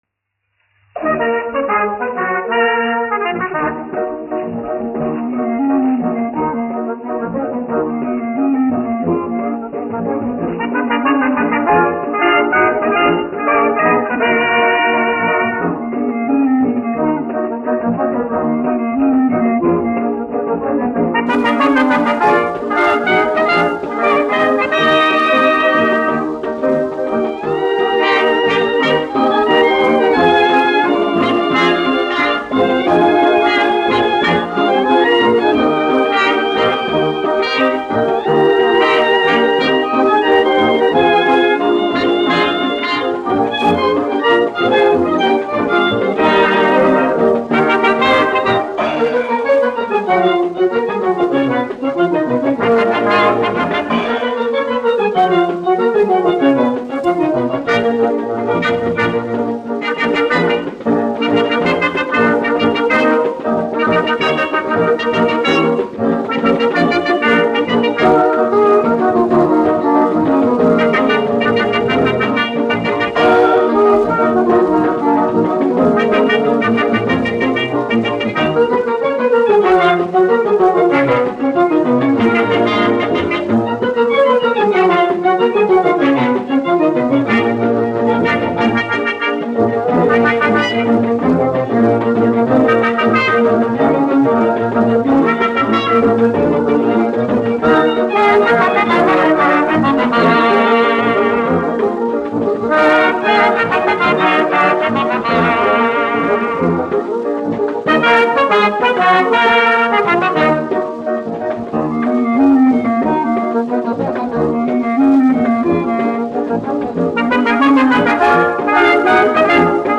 1 skpl. : analogs, 78 apgr/min, mono ; 25 cm
Kinomūzika
Džezs
Skaņuplate